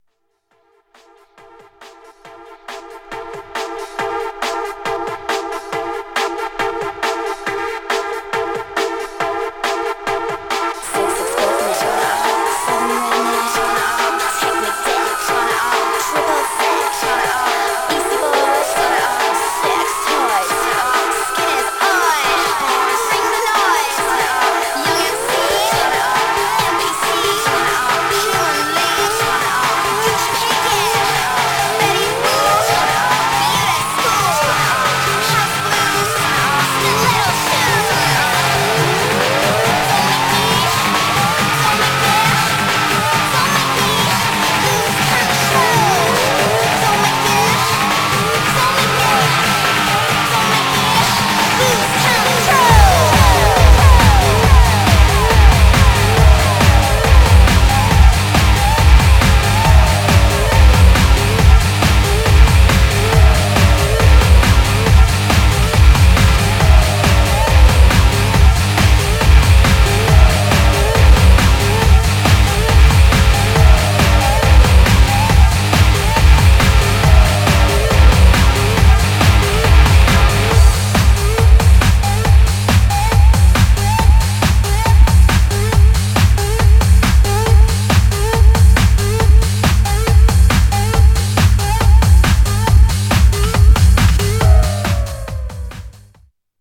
Styl: Breaks/Breakbeat
Dub Mix